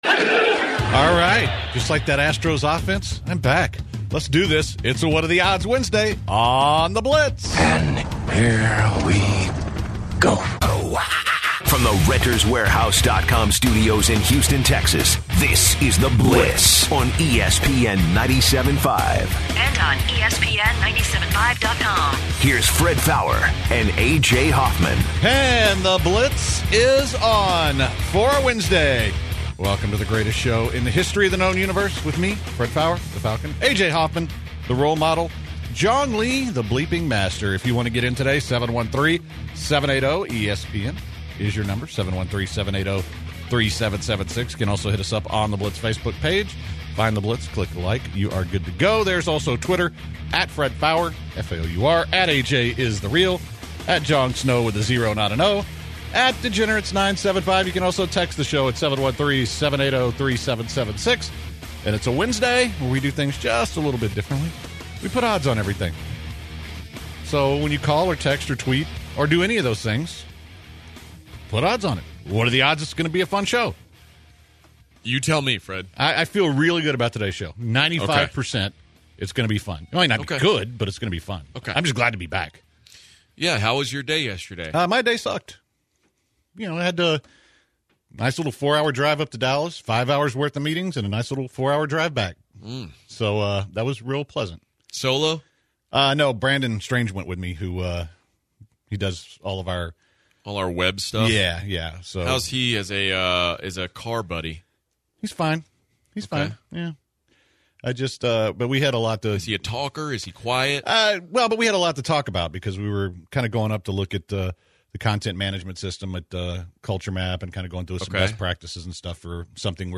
It's a what are the odds Wednesday on The Blitz. The calls were plentiful today ranging from Texans draft needs to rising boxing star Lomachenko.